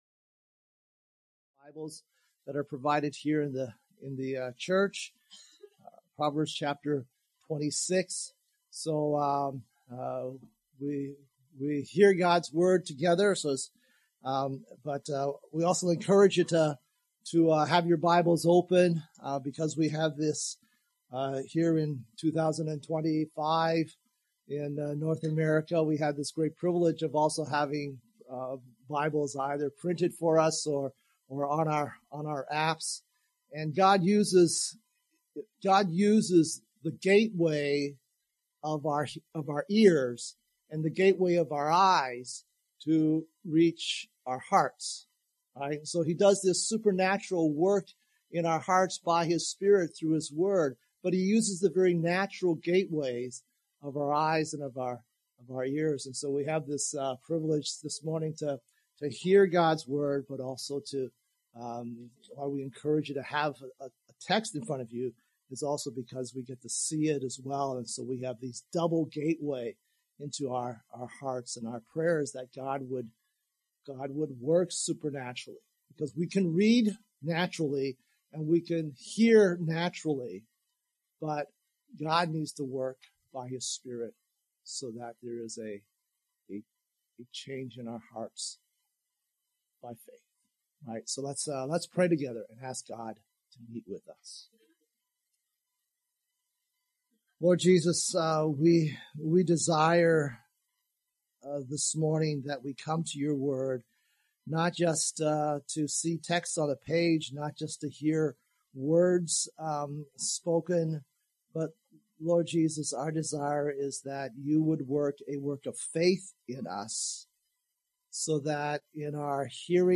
2025 Portrait of a Fool Preacher